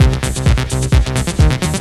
TECHNO125BPM 21.wav